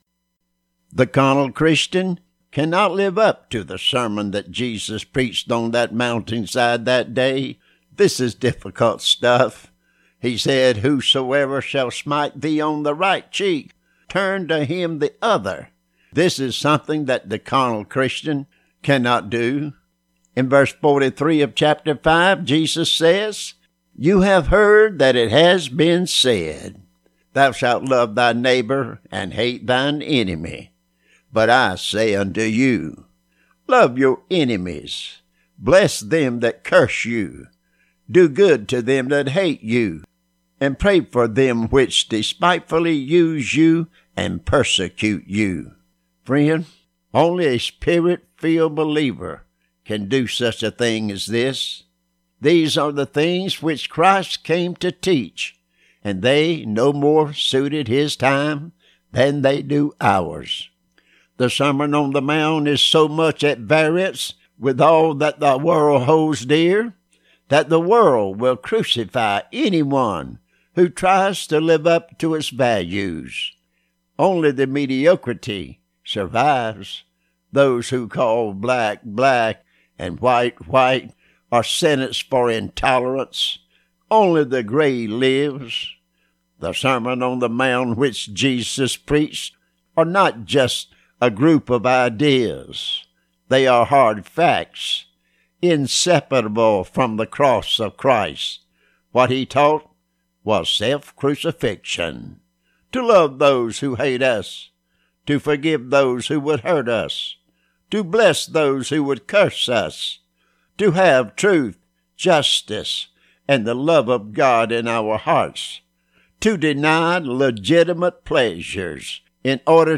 Sermon on The Mountain _Friday